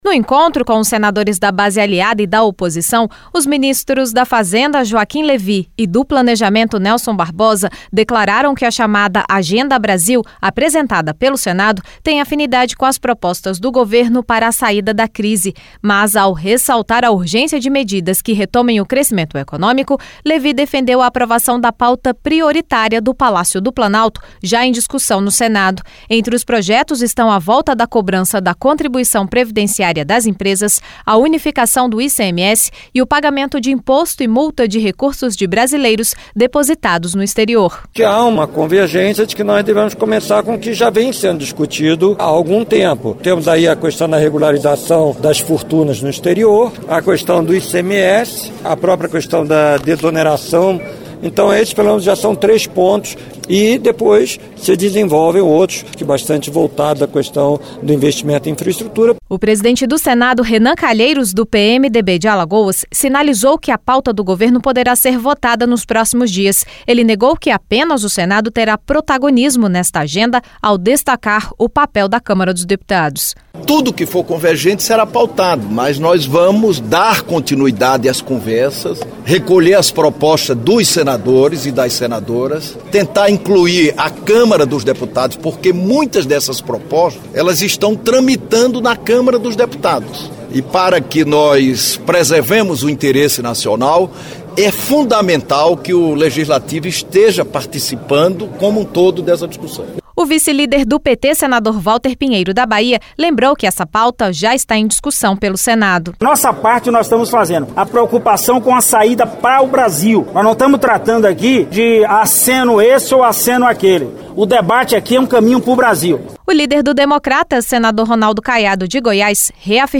Reunião no Senado